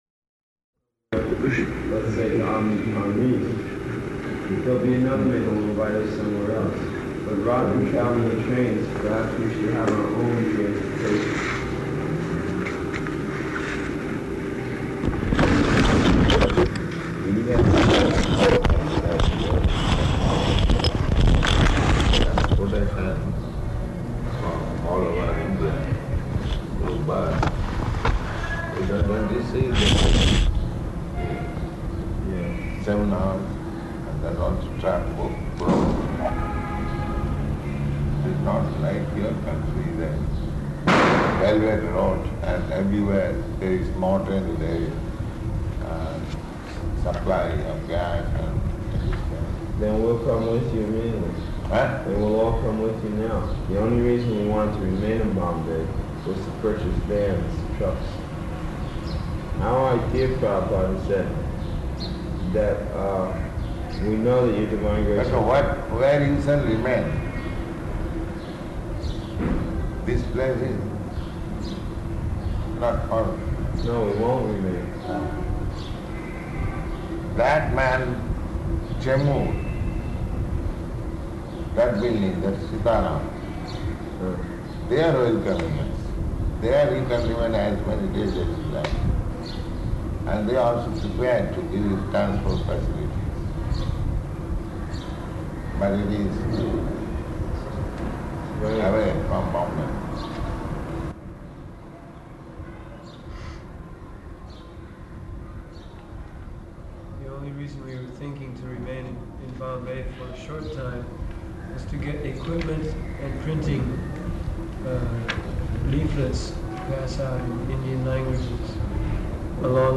Conversation [partially recorded]
-- Type: Conversation Dated